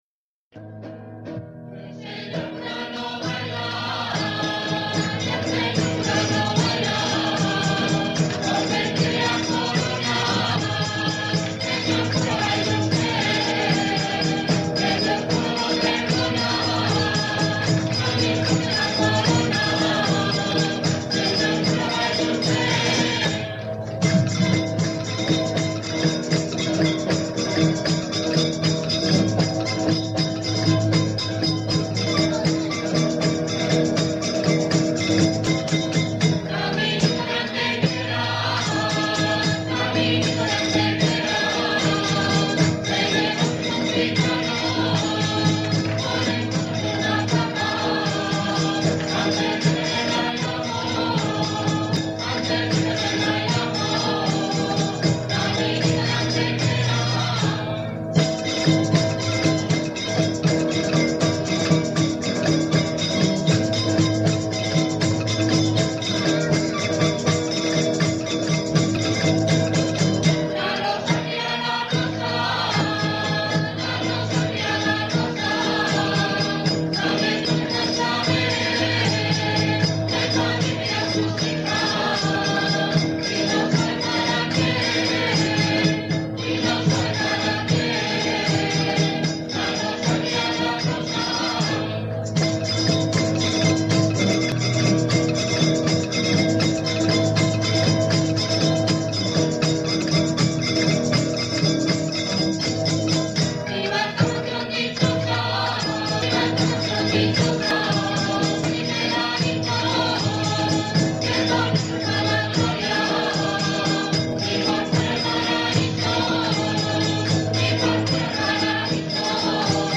Canciones regionales
Coro OJE Retiro-Vallecas
Jota de Torreiglesias (Popular – Segovia)